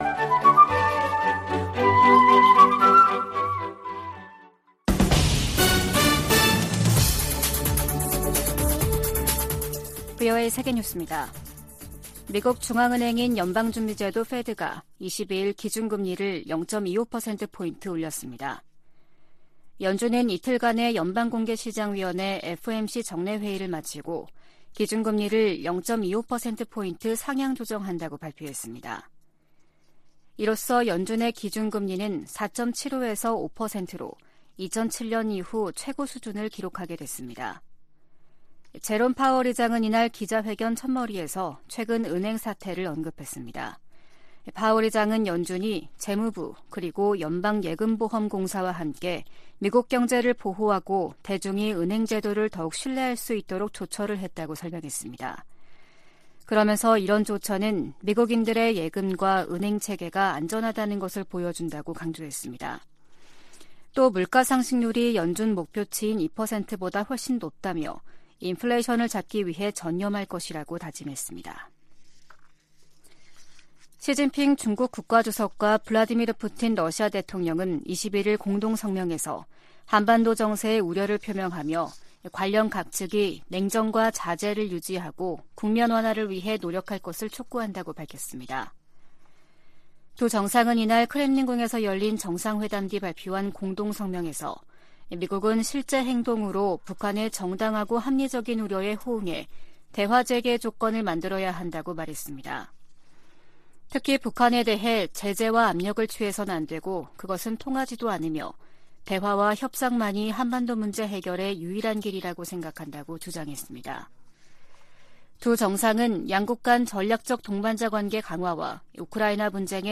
VOA 한국어 아침 뉴스 프로그램 '워싱턴 뉴스 광장' 2023년 3월 23일 방송입니다. 북한이 또 다시 순항미사일 여러 발을 동해상으로 발사했습니다. 미국 정부는 모의 전술핵 실험에 성공했다는 북한의 주장에 우려를 표했습니다. 미국 국방부가 북한의 미사일 프로그램이 제기하는 도전을 면밀히 감시하고 있다고 강조했습니다.